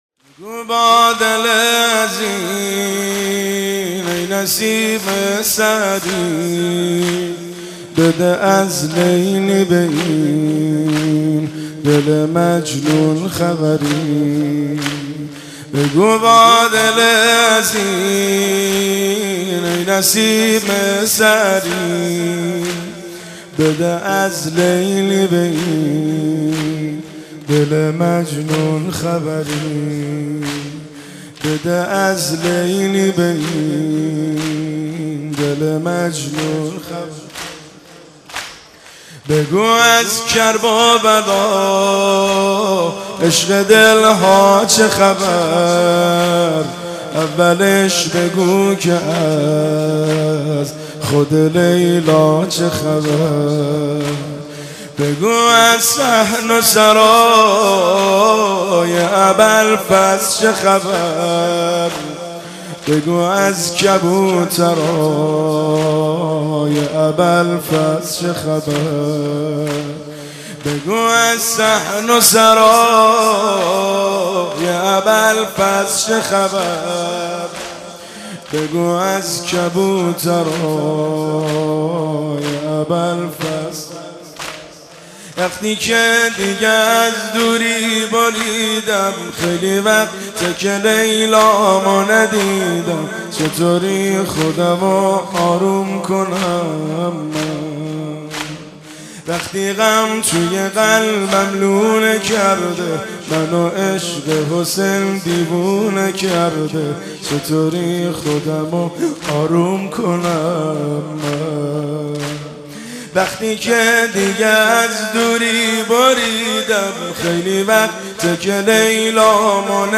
مداحی بگو با دل حزین،ای نسیم سحری(زمینه)
شام غریبان محرم 1392
هیئت خادم الرضا(ع) قم